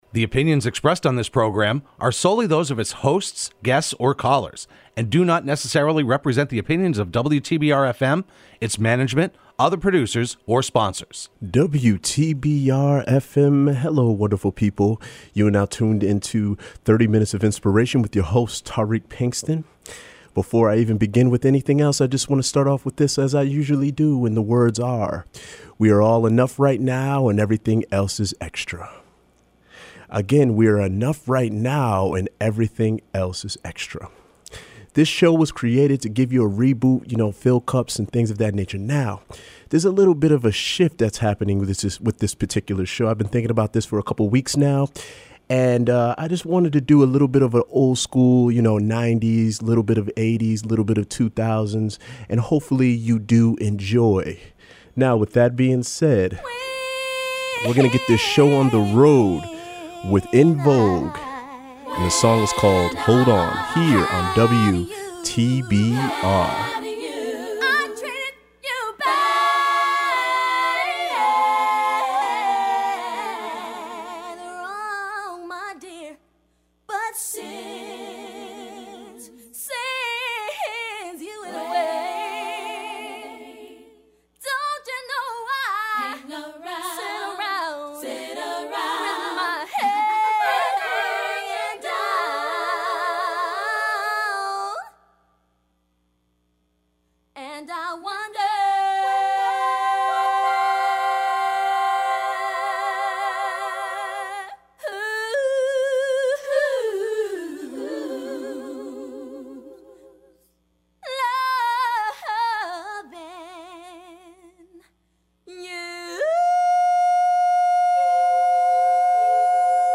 broadcast live every Friday at 12:30pm on WTBR.